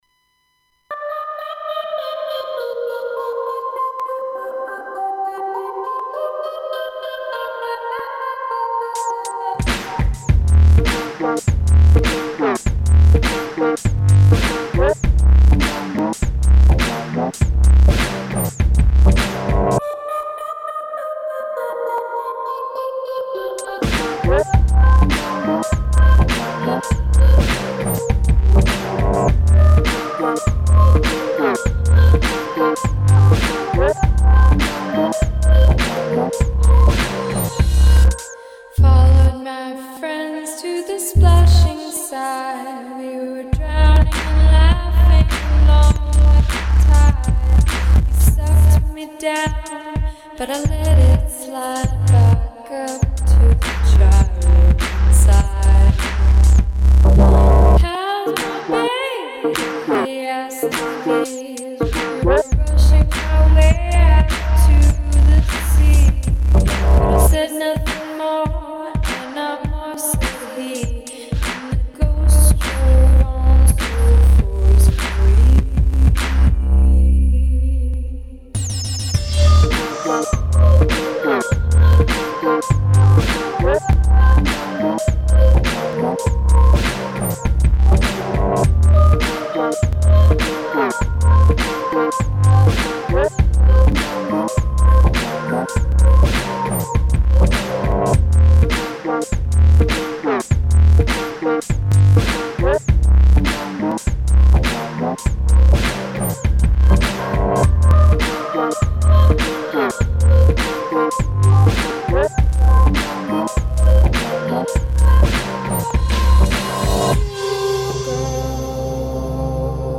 XXI. mendeko soul eta r&b doinuak